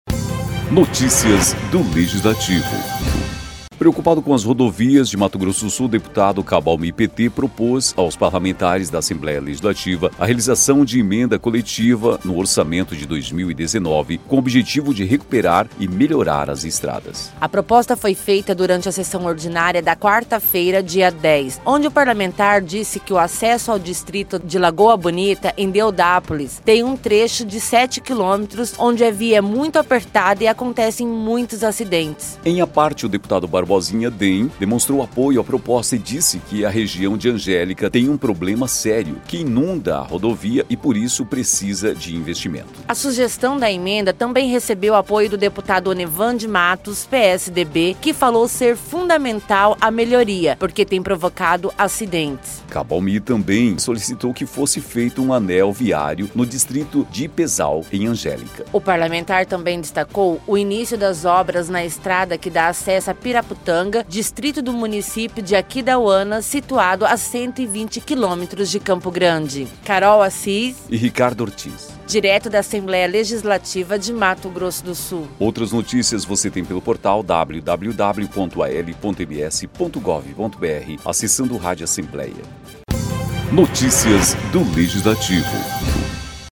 A proposta foi feita durante a sessão ordinária desta quarta-feira (10).